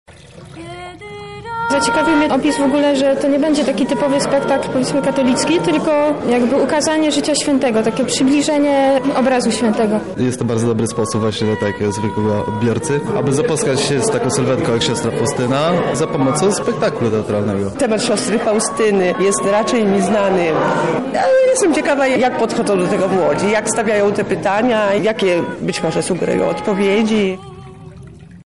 Swoimi wrażeniami po spektaklu podzielili się jego widzowie.